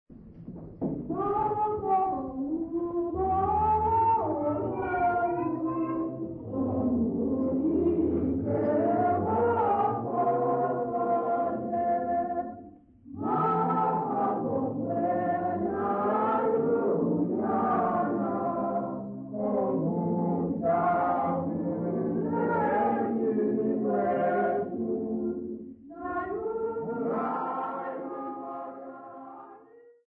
St Joseph's congregation
Sacred music South Africa
Africa South Africa Grahamstown, Eastern Cape sa
field recordings
Unaccompanied Catholic mass hymn